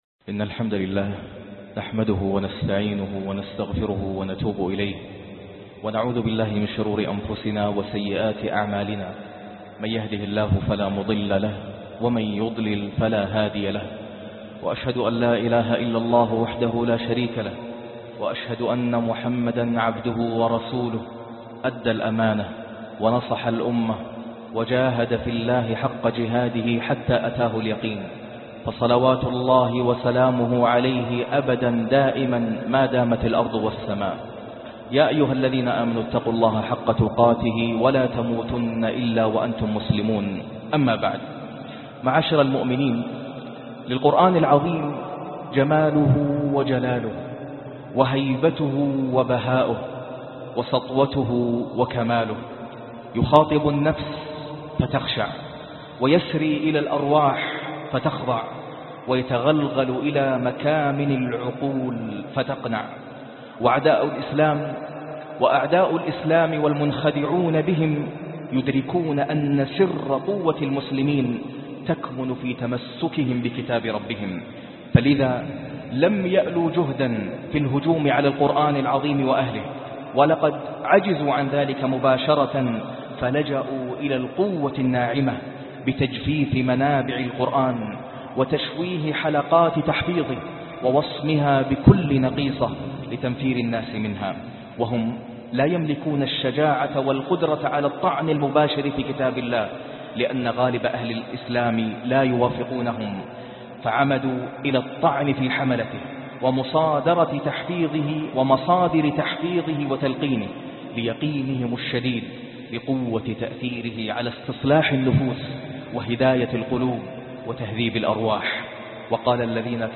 أثر تعلم القرآن العظيم علي النوغ العلمي - خطبة الجمعة - القاريء ناصر القطامي